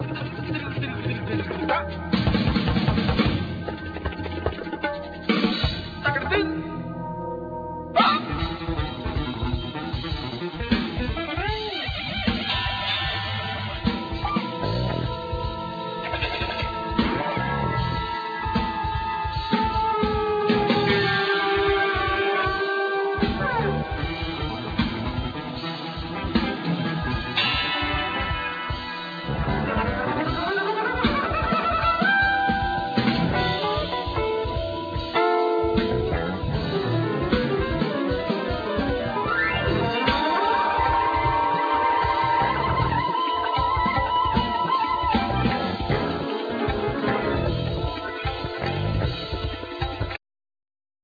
Drums,Gongs
Tabla,Percussions,Voice
Acoustic Bass
Saxophones,Flute
Electric Bass
Keyboards,Piano